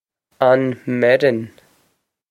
On mer-on
This is an approximate phonetic pronunciation of the phrase.